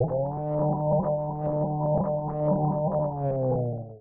boost.wav